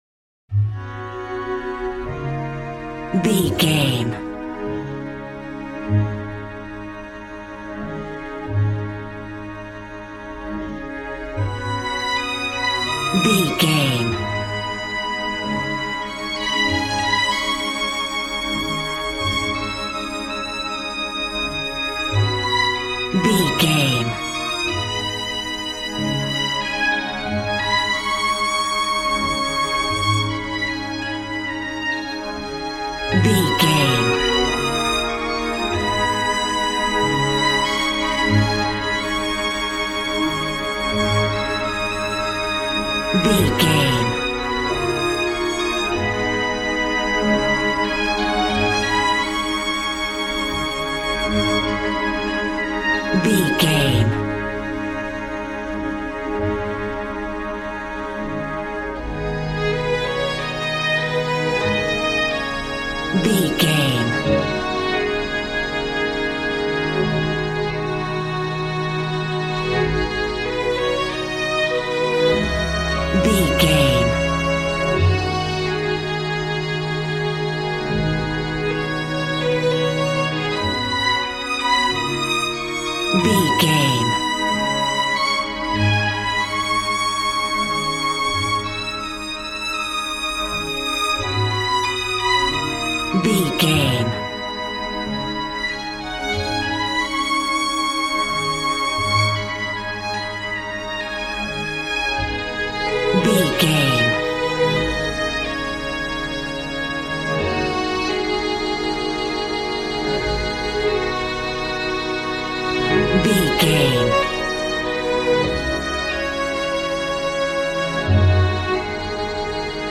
Aeolian/Minor
A♭
joyful
conga
80s